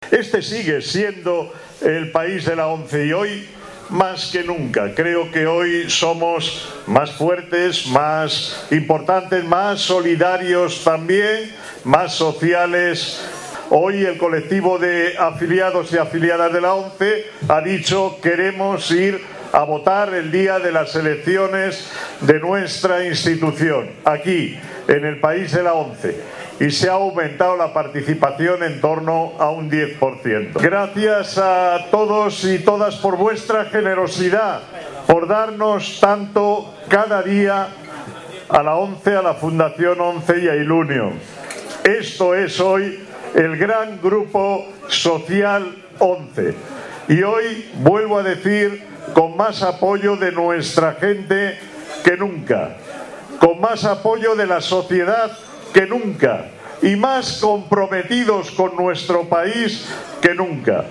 expresaba así formato MP3 audio(0,93 MB) su valoración ante el futuro en la culminación de la noche electoral.